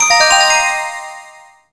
match-start.wav